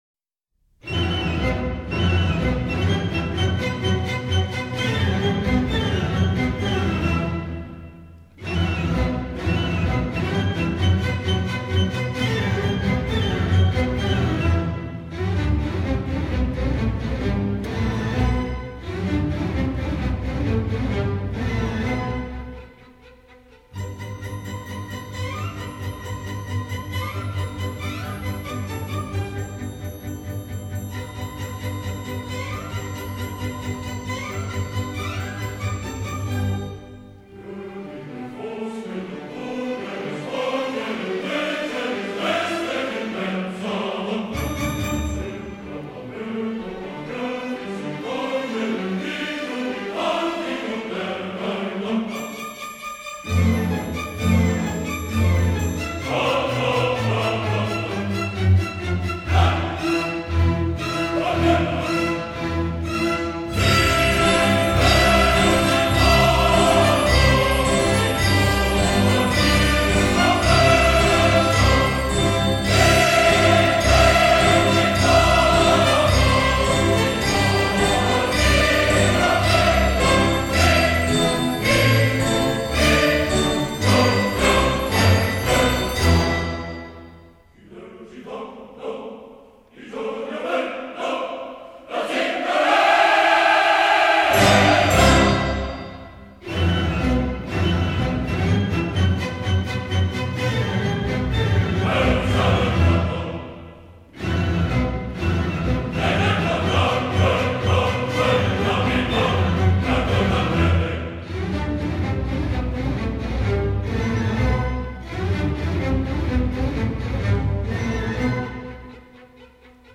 这里选用了其中的两首，一首是第二幕中吉普赛人挥舞铁锤打铁时所唱的"打铁合唱曲"，另一首是第三幕中卢纳伯爵的卫队长费兰多和他的军队所唱的合唱曲。